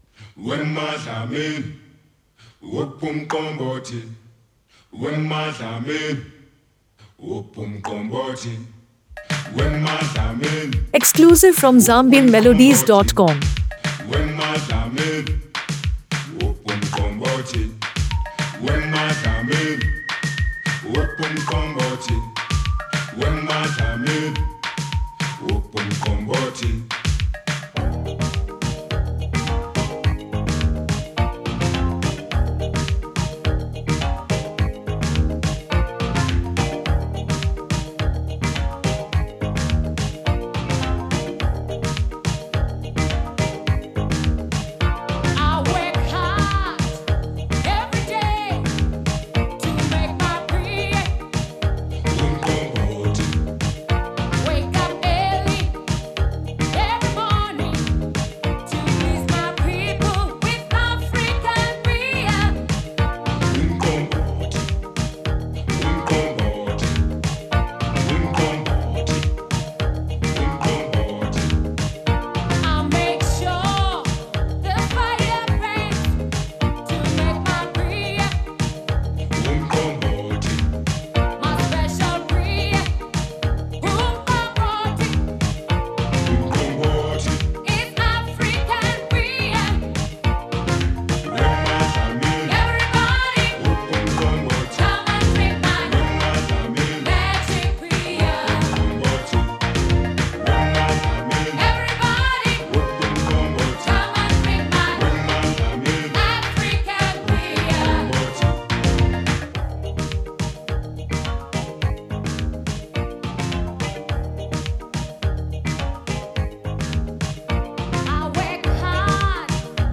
Foreign Music